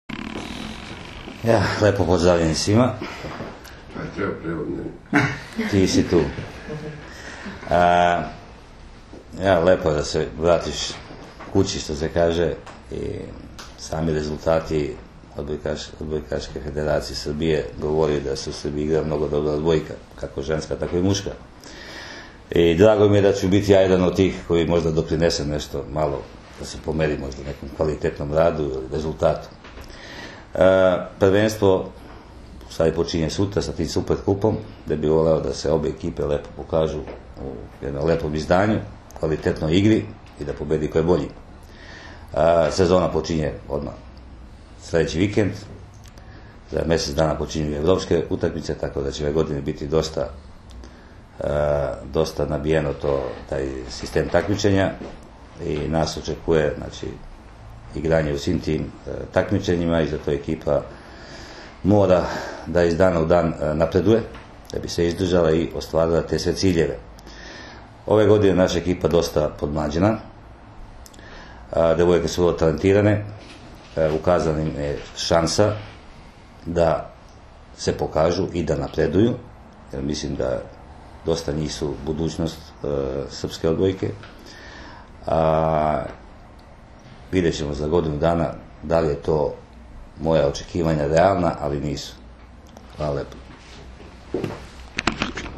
U prostorijama Odbojkaškog saveza Srbije danas je održana konferencija za novinare povodom utakmice IV Super Kupa Srbije 2016. u konkurenciji odbojkašica, koja će se odigrati sutra (četvrtak, 13. oktobar) od 18,00 časova u dvorani SC “Vizura” u Beogradu, između Vizure i Jedinstva iz Stare Pazove, uz direktan prenos na RTS 2.
IZJAVA